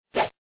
OldJump.mp3